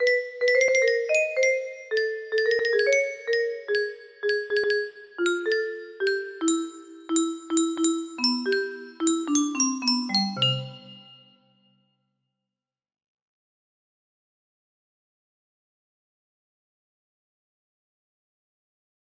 Unknown Artist - Untitleddsf music box melody